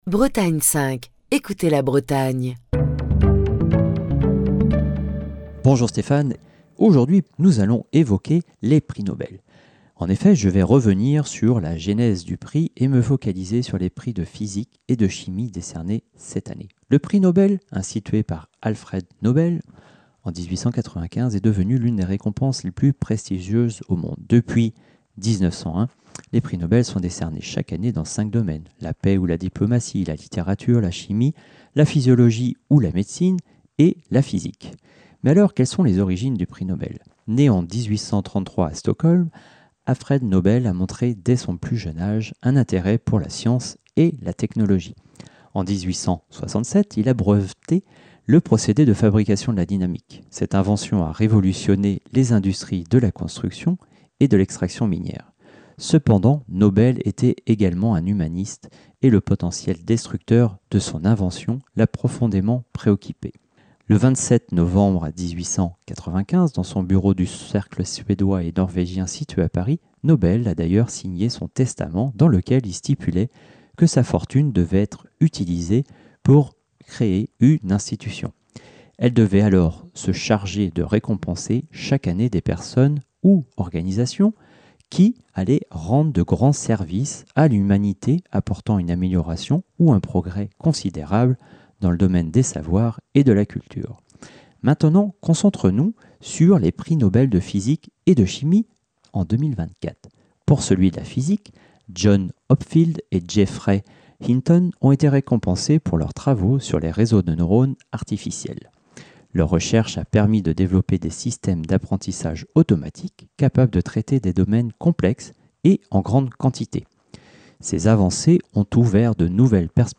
Chronique du 23 octobre 2024.